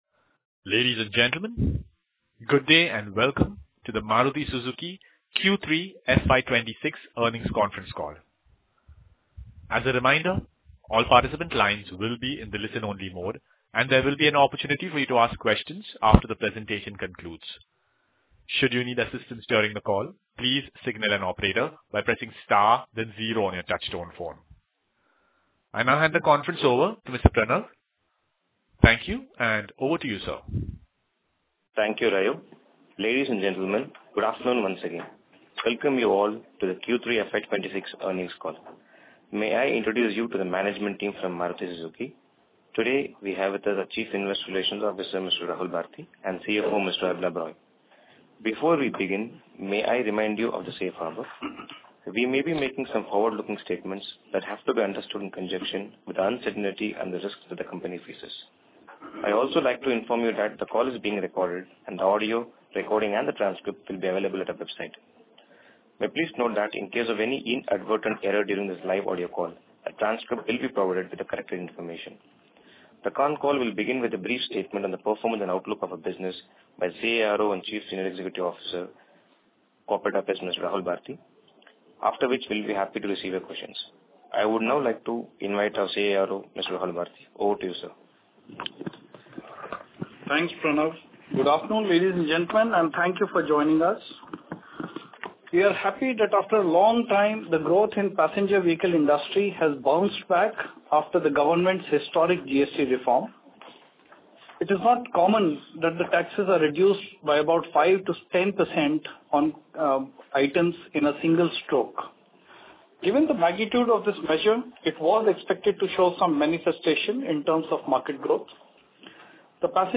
Audio recording – Q3FY24, Investor/Analyst conference call